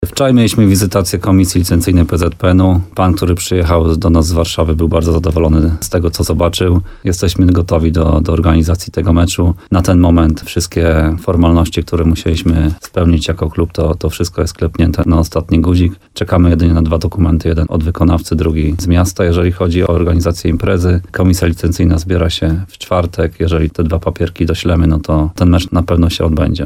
mówił w programie Słowo za Słowo na antenie RDN Nowy Sącz